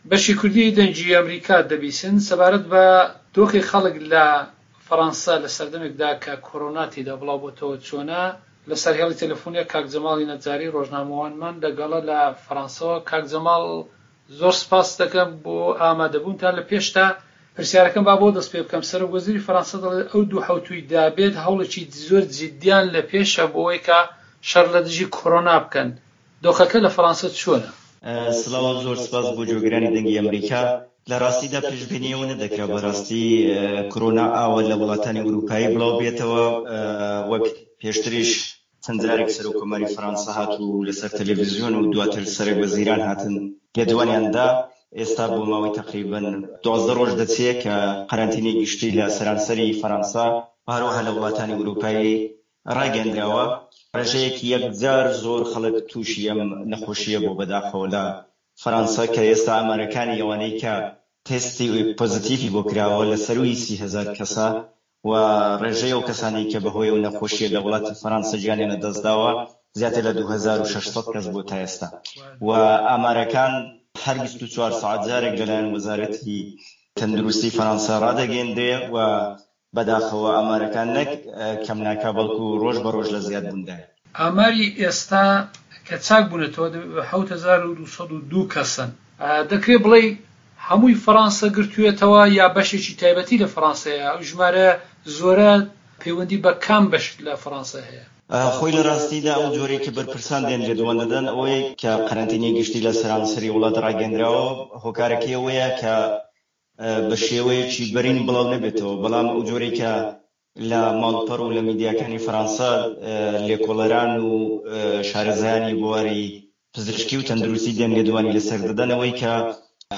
جیهان - گفتوگۆکان